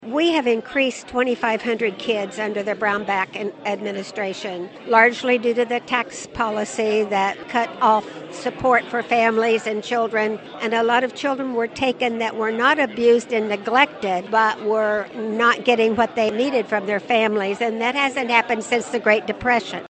MANHATTAN — The Manhattan Area Chamber of Commerce hosted its second legislative coffee Saturday at Sunset Zoo.
Carlin explained the current state of affairs for the Kansas Department for Children and Families.